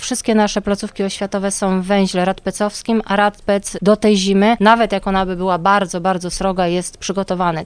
W Radomiu nie zamkniemy szkół pod pretekstem niskiej temperatury, dodaje Katarzyna Kalinowska: